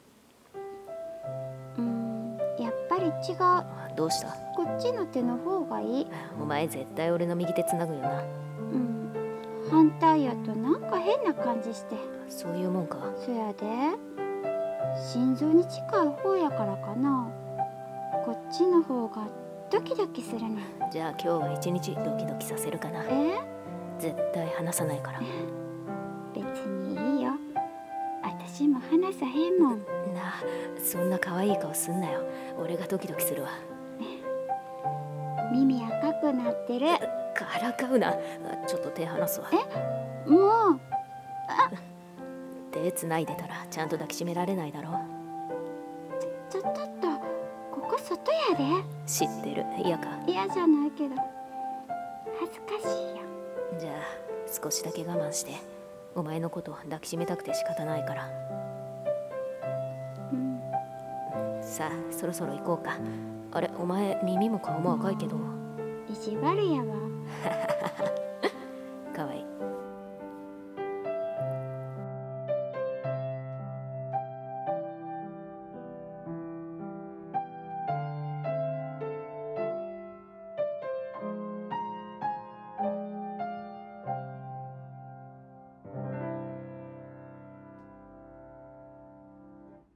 💖1⃣声劇【離さない手】※恋愛コラボ声劇 / 朗読